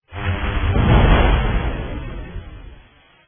enemy3_down.wav